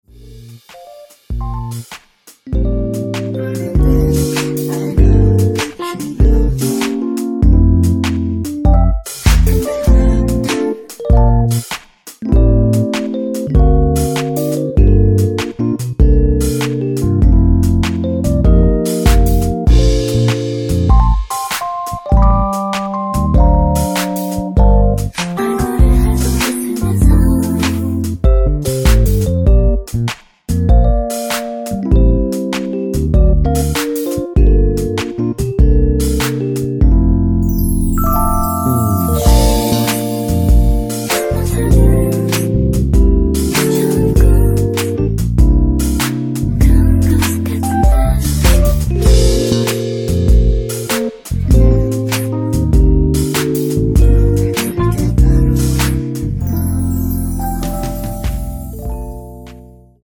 원키에서(+1) 올린 코러스 포함된 MR 입니다.
앞부분30초, 뒷부분30초씩 편집해서 올려 드리고 있습니다.
중간에 음이 끈어지고 다시 나오는 이유는